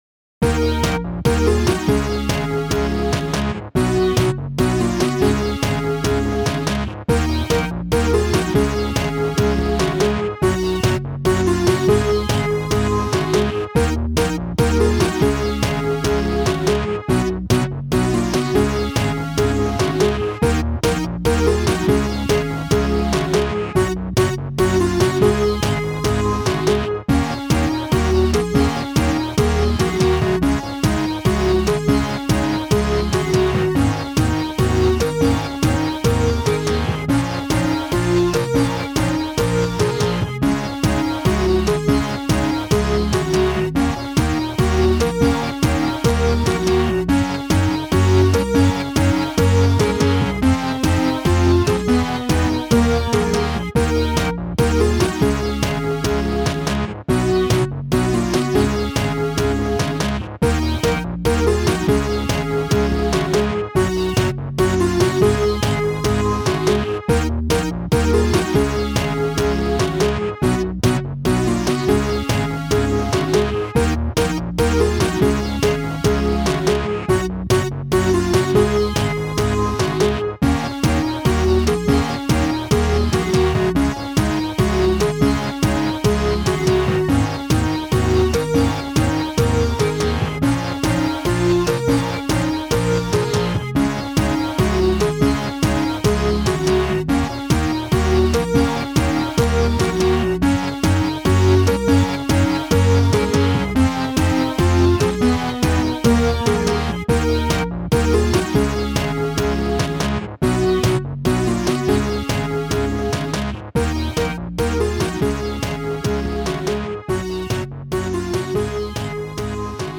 Upbeat epic track for retro and platform worlds.
Upbeat track for retro and platform worlds.